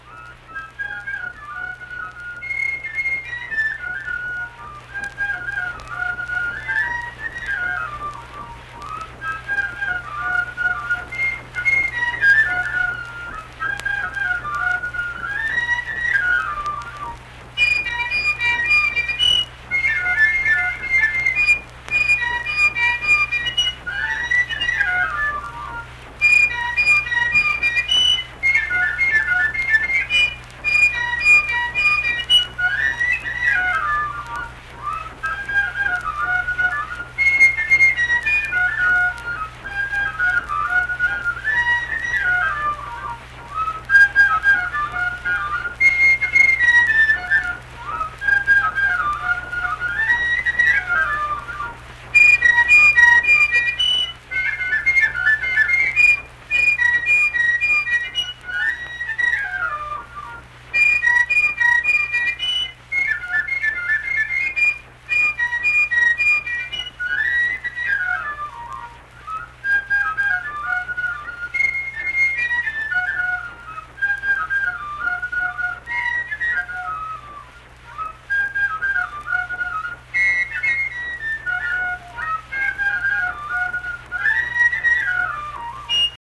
Accompanied fife music
Performed in Hundred, Wetzel County, WV.
Instrumental Music
Fife